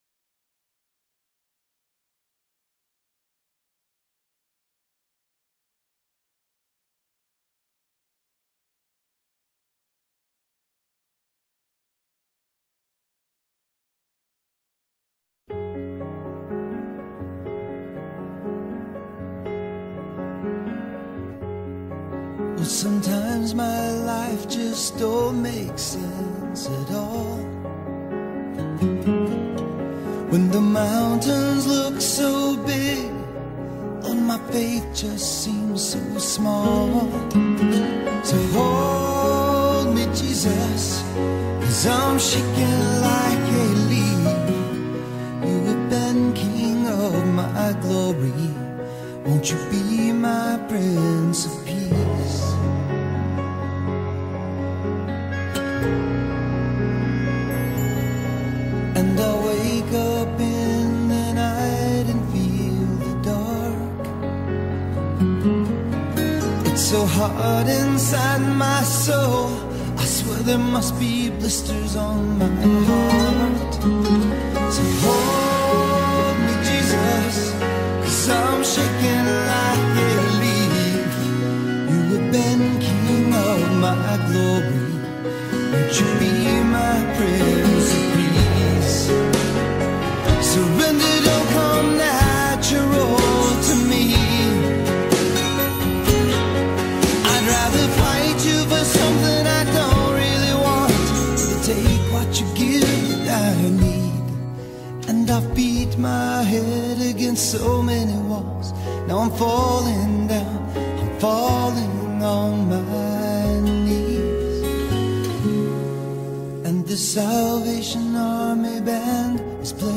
Sermons on THE Sermon Godly Sex!